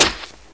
/hl2/sound/npc/antlion_guard/near/
foot_light1.ogg